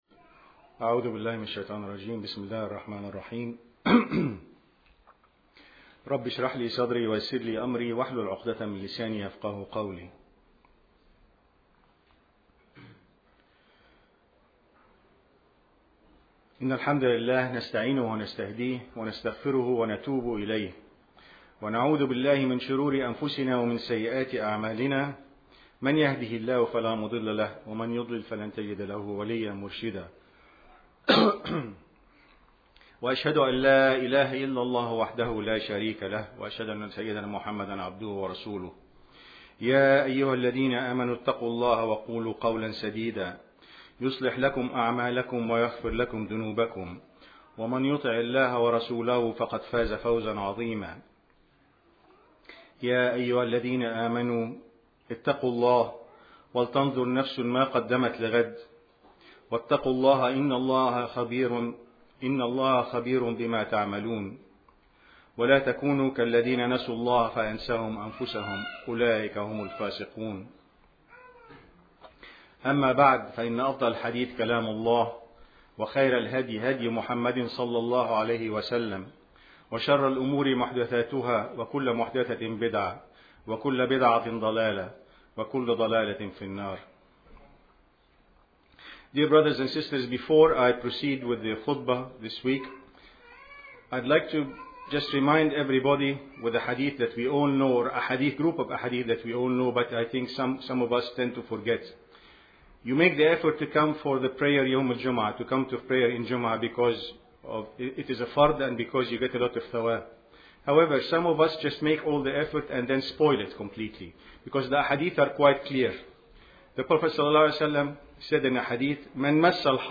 To hear this audio khutbah, please click here: HR Good from Allah Evil from ourselves